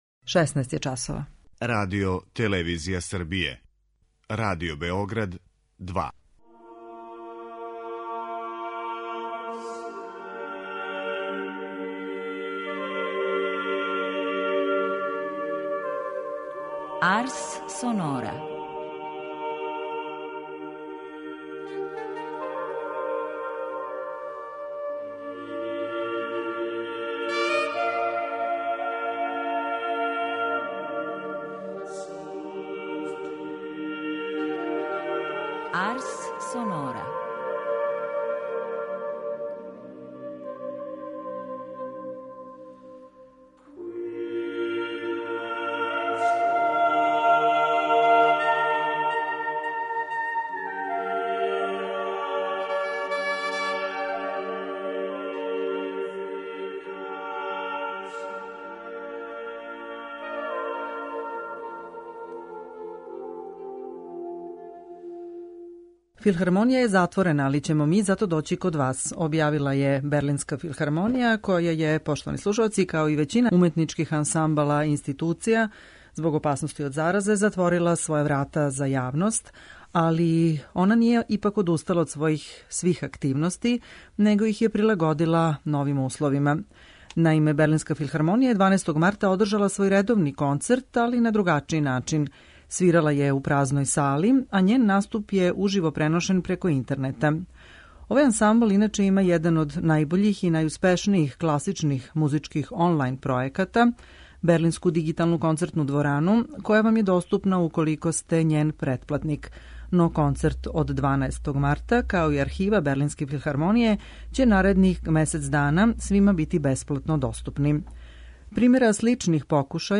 три сонате
руско-немачки пијаниста Игор Левит